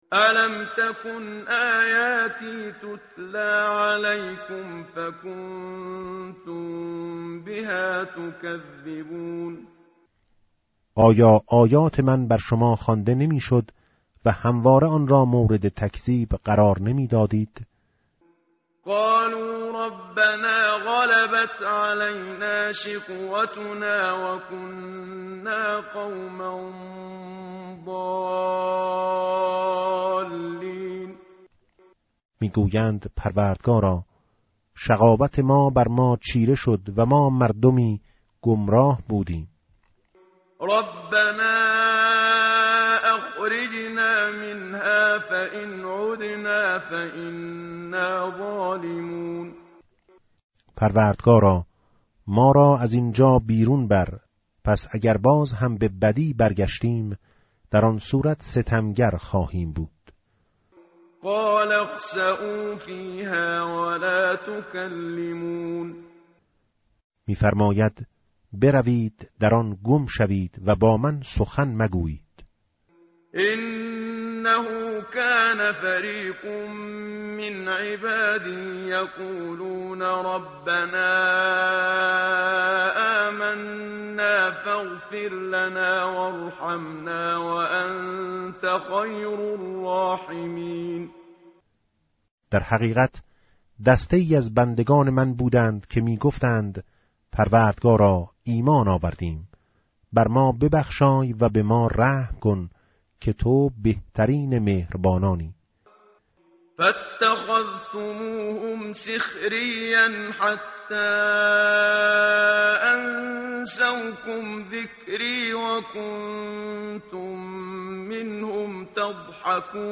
متن قرآن همراه باتلاوت قرآن و ترجمه
tartil_menshavi va tarjome_Page_349.mp3